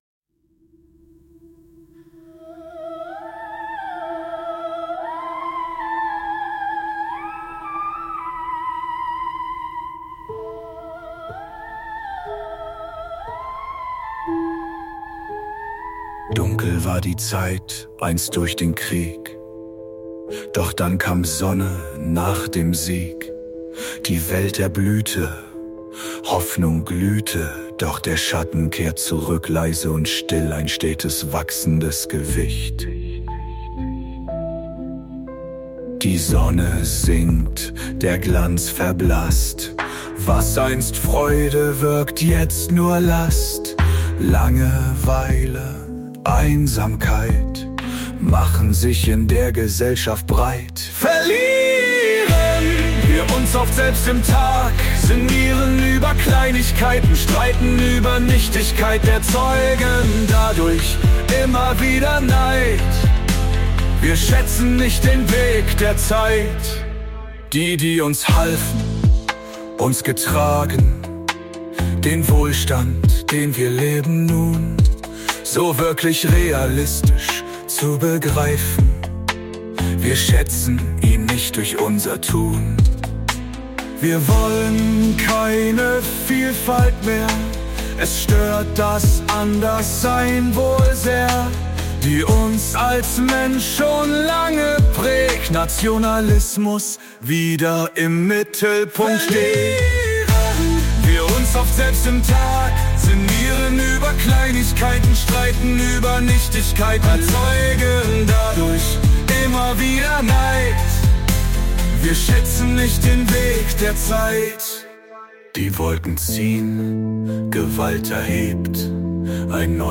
Ich habe meine Frustration in mehrere Texte gesteckt und mit KI vertont…